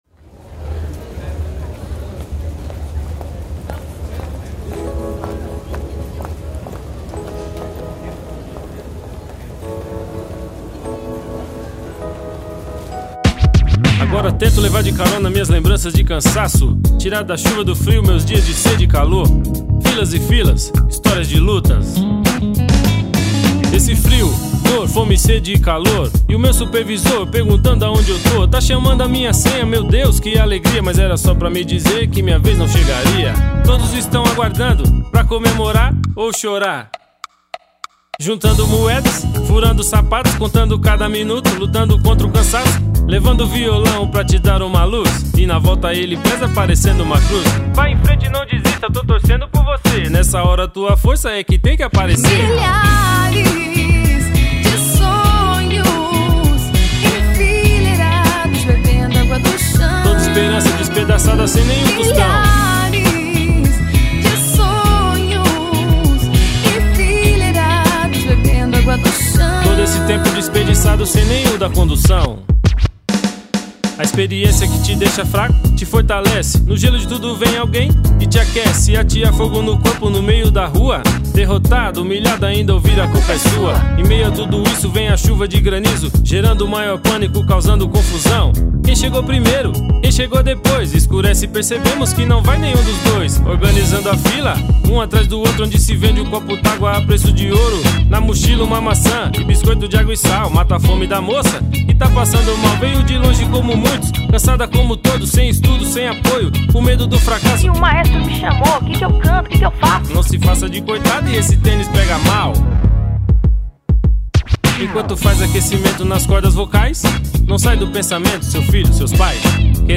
EstiloMPB